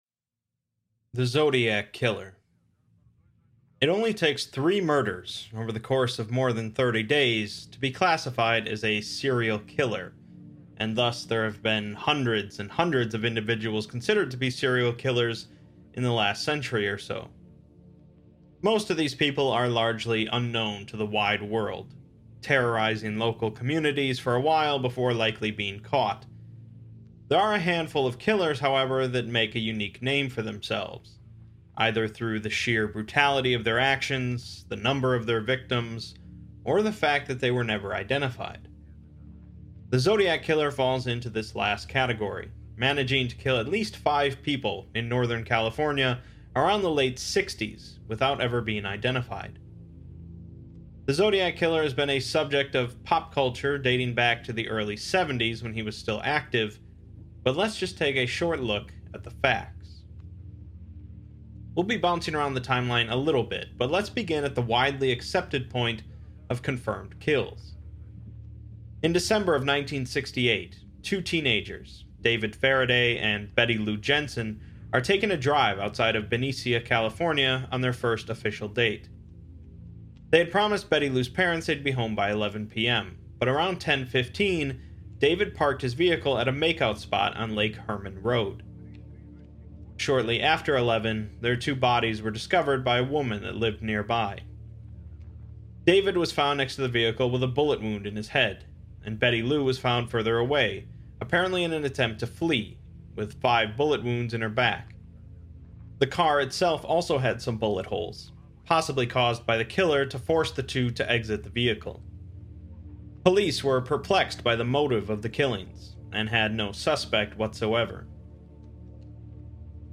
To enhance your listening experience and support our work, advertisements are configured only at the beginning of each episode.